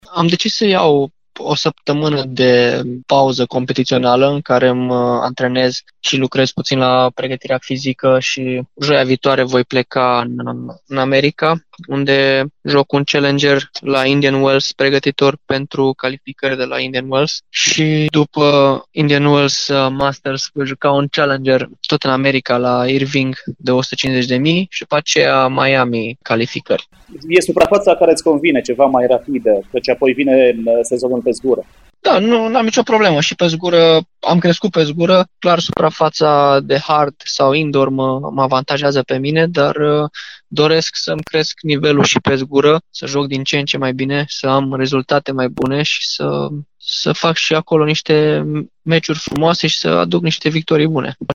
Într-un interviu la postul nostru de radio, Marius Copil a precizat că se pregăteşte de sezonul american de primăvară cu turnee challenger dar şi de 1.000 de puncte: